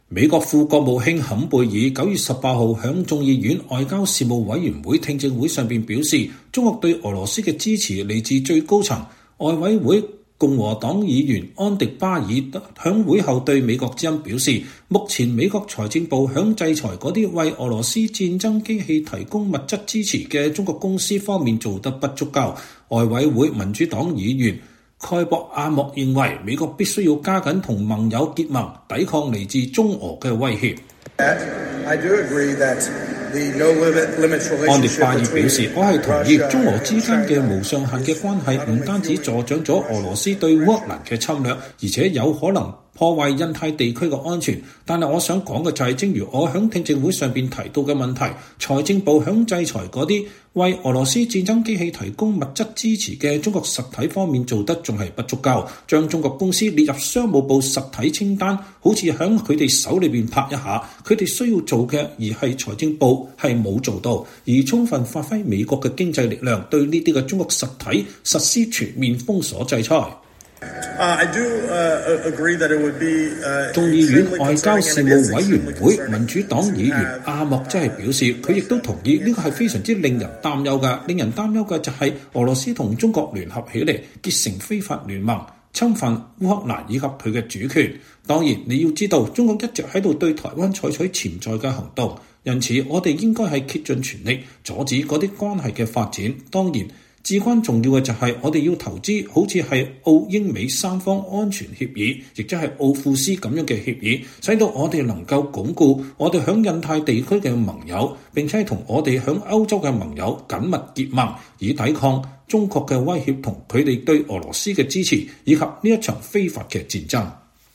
外委會共和黨議員安迪·巴爾(Andy Barr)在會後對美國之音表示，目前美國財政部在製裁那些為俄羅斯戰爭機器提供物質支持的中國公司方面做得不夠。外委會民主黨議員蓋博·阿莫(Gabe Amo)認為，美國必須加緊與盟友結盟，抵抗來自中俄的威脅。